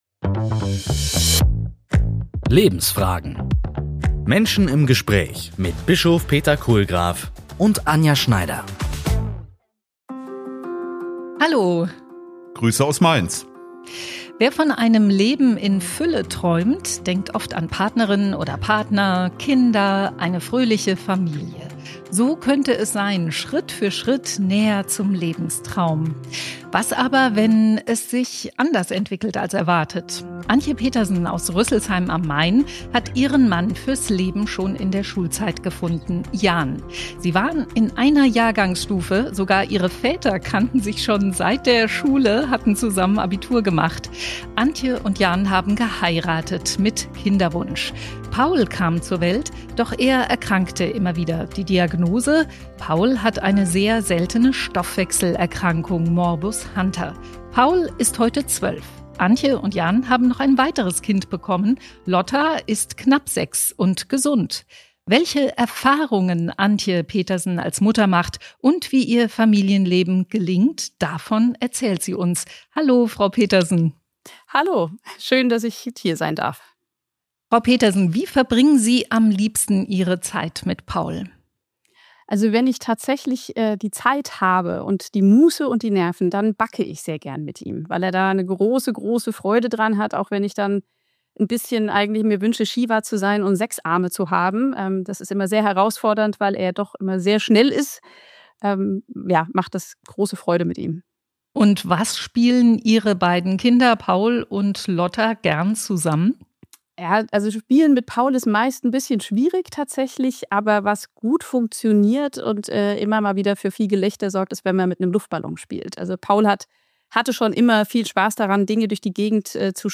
Menschen im Gespräch